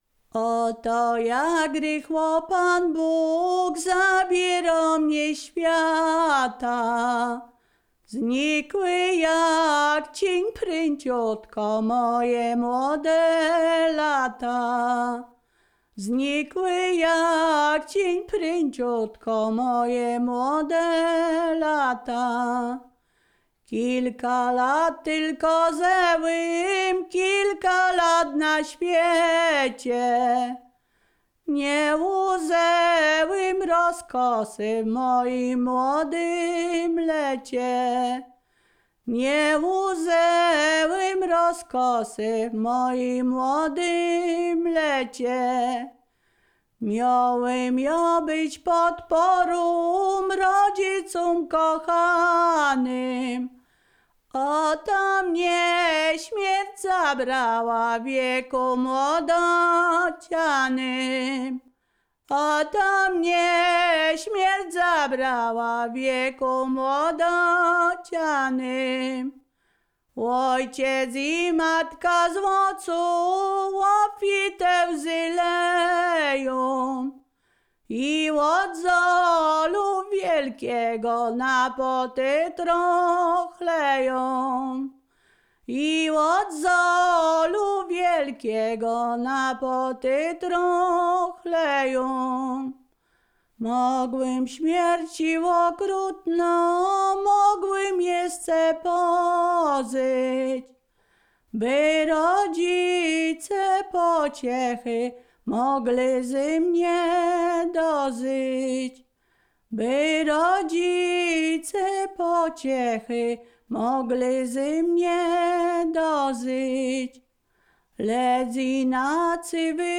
Ziemia Radomska
Pogrzebowa
pogrzebowe nabożne katolickie do grobu